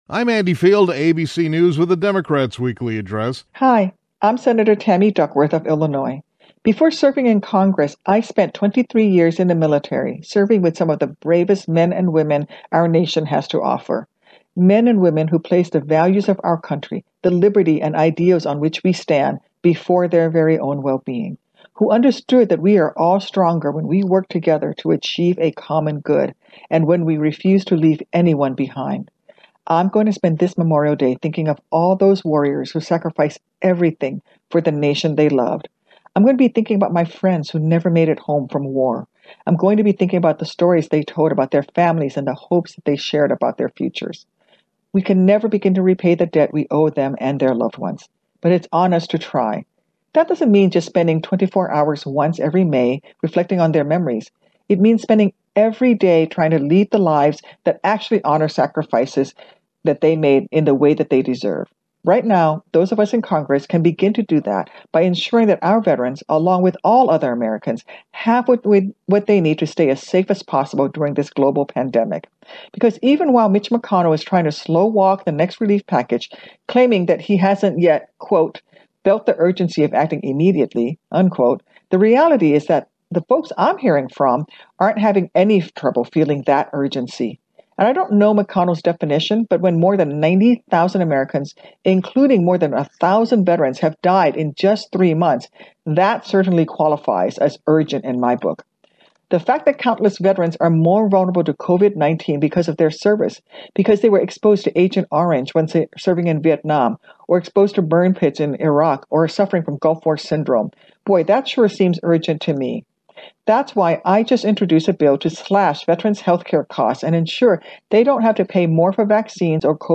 During the Democratic Weekly Address, Sen. Tammy Duckworth (D-IL) stated that passing another coronavirus relief package is urgent, and that Congress can help to honor the sacrifices of America’s fallen veterans by ensuring that veterans don’t have to pay more for vaccines or COVID-19 preventive services than any other insured American.